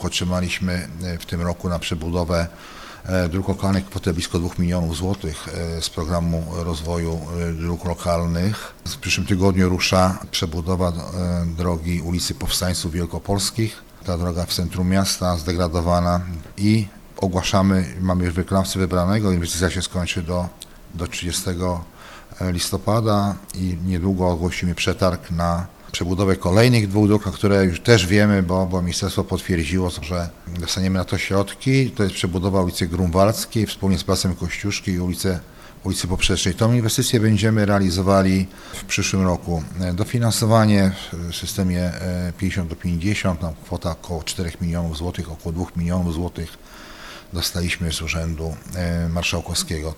Między innymi ulicę Powstańców Wielkopolskich ,a w następnym etapie ulicę Grunwaldzką z Placem Kościuszki – mówi burmistrz Lesław Hołownia: